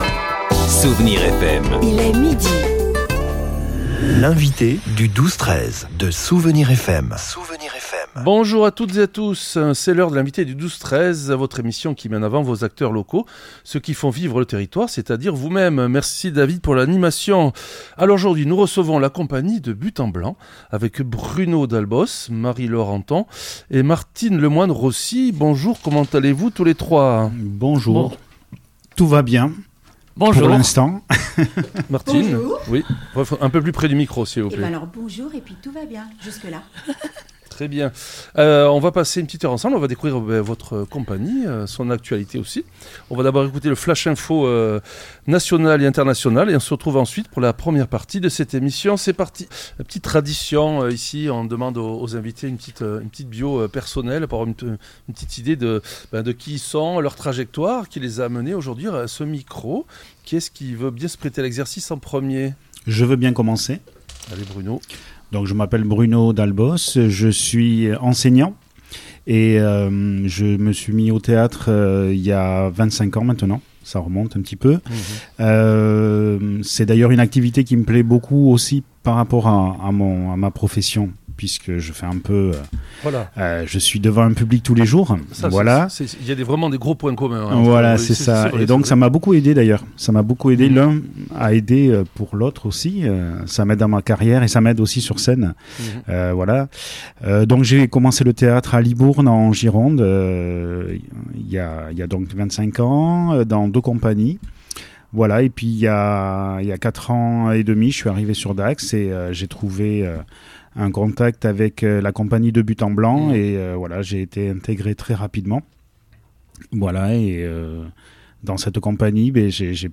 L'entretien nous a plongés dans les coulisses d'une aventure collective où chacun met la main à la pâte, de la mise en scène démocratique à la création artisanale des décors et costumes.